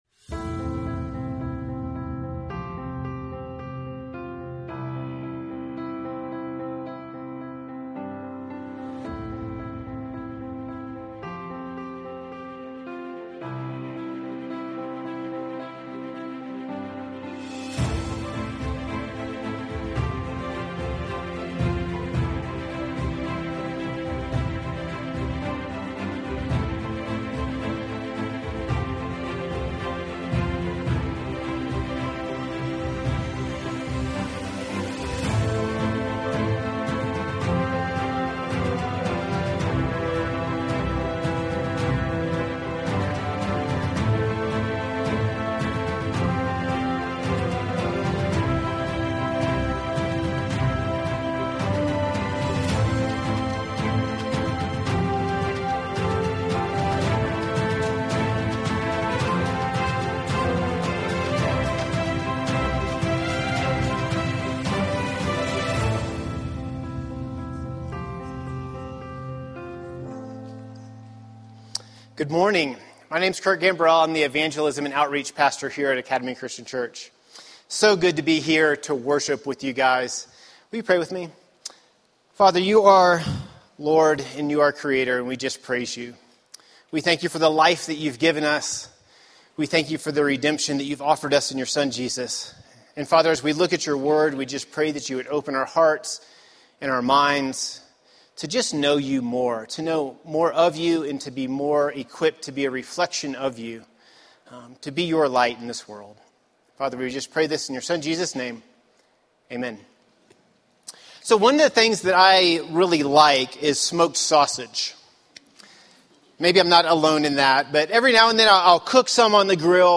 A message from the series "Life is Better Together."